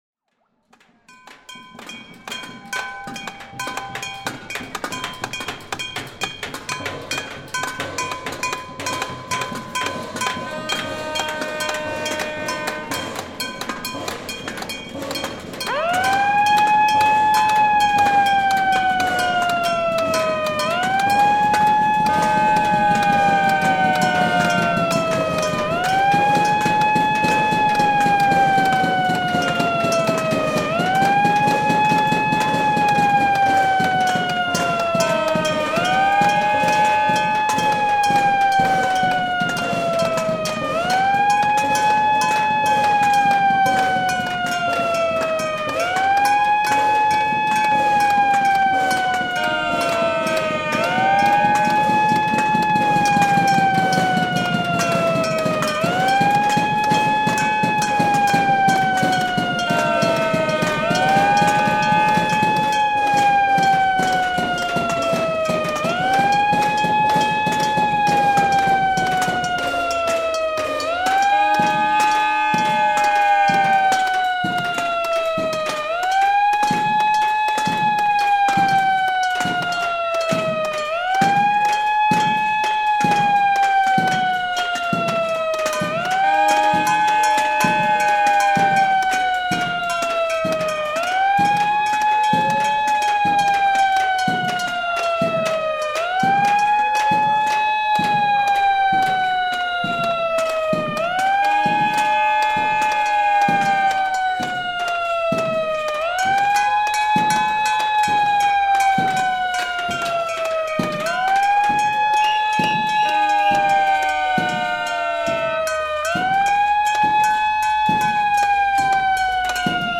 Það er því miður fámennur hópur fólks sem gefið hefur sér tíma í hádeginu til að mæta niður á Hverfisgötu framan við greni AGS og mótmælt úreltum hagstjórnargjörningum þeirra. En þótt hópurinn sé fámennur þá er hann býsna hávær.
Notast var við Rode NT4 hljóðnema, Sound Device 305 formagnara og Korg MR1000.